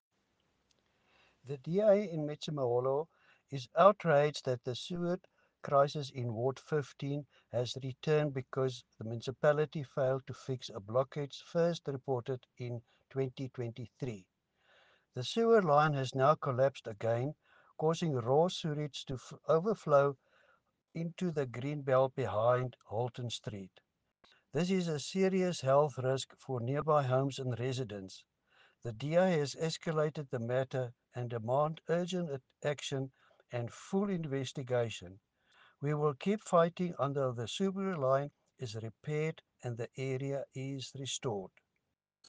Issued by Cllr. Louis van Heerden – DA Councillor Metsimaholo Local Municipality
English and Afrikaans soundbites by Cllr Louis van Heerden and Sesotho soundbite by Cllr Kabelo Moreeng.